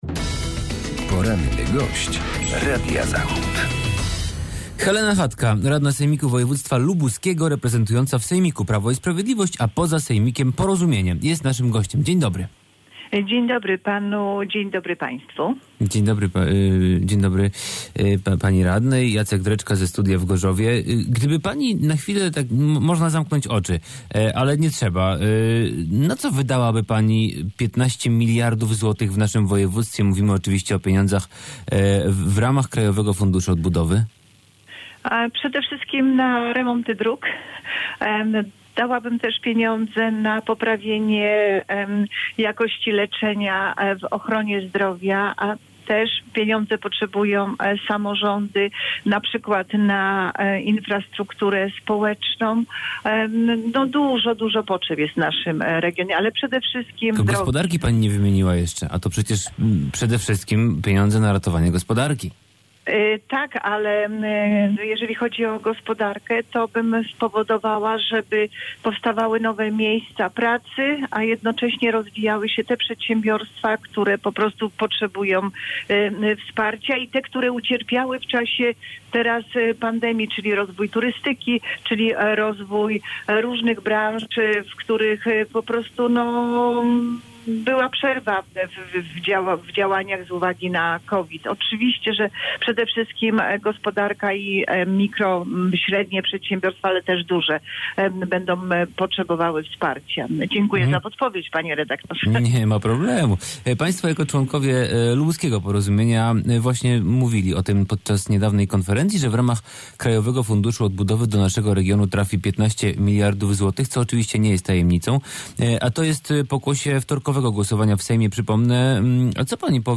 Z radną sejmiku wojewódzkiego, członkiem rady krajowej Porozumienia rozmawia